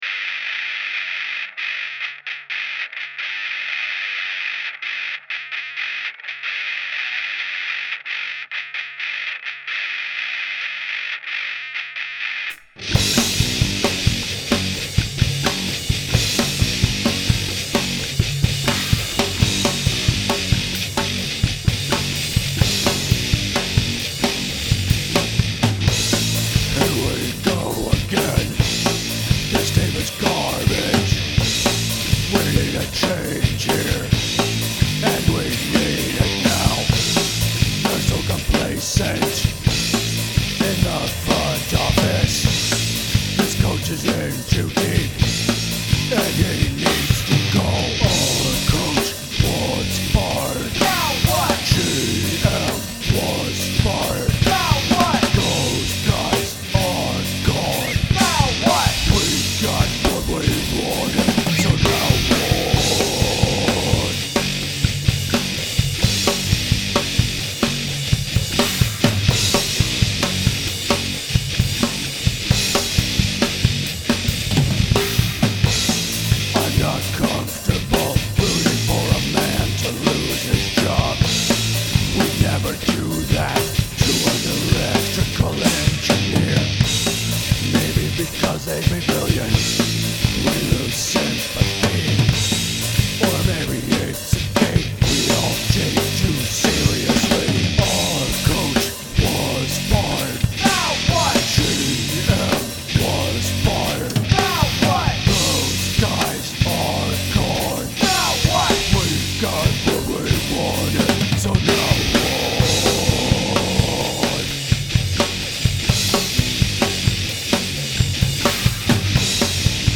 I’ve been obsessed with music in 7/4 and 7/8 time for years, so combine that obsession with the 8-string guitar and we get some new ideas on how to be heavy in compound time. I added some other riffs in different meters (including a clean section) and realized I had a bit of a winner.